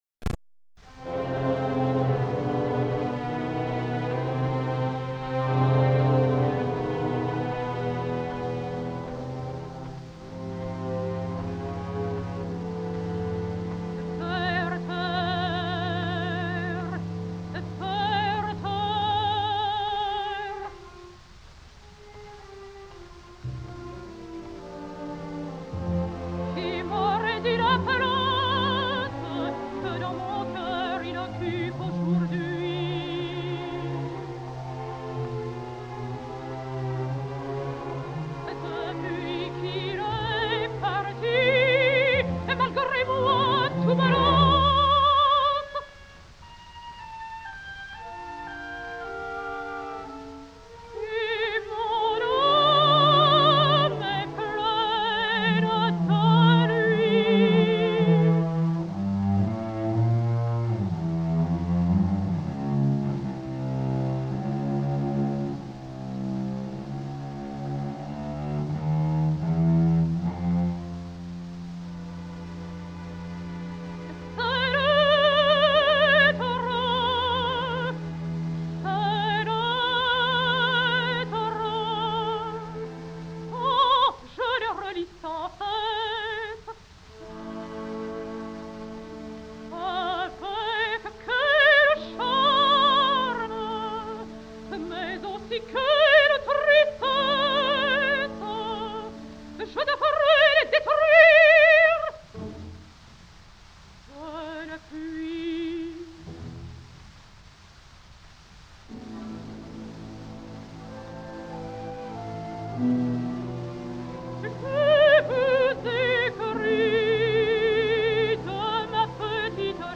L’harmonia és inestable amb constants modulacions que il·lustren l’agitació del personatge.
Qui m’aurait dit la place que dans mon coeur” (ària de les cartes)